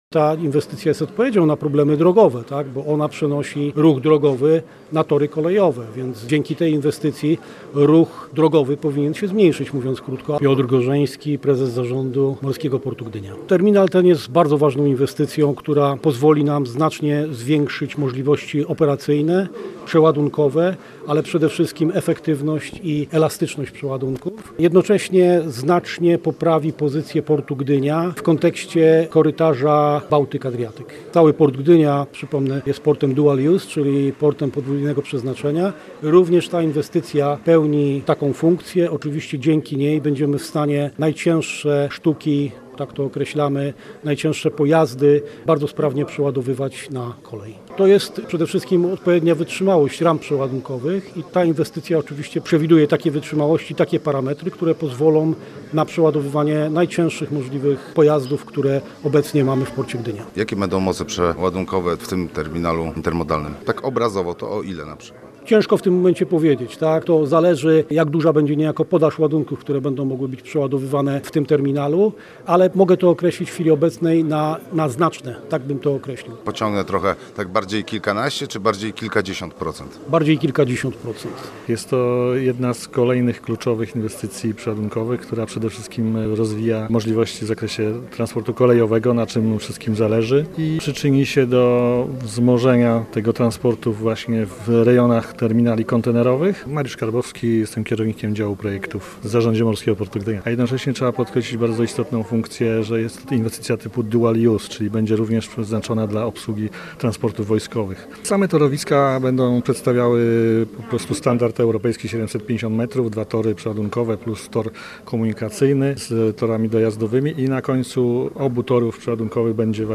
INWESTYCJA ZA MILIONY Koszt budowy terminalu intermodalnego pochłonie ponad 70 milionów złotych. Posłuchaj materiału naszego reportera: https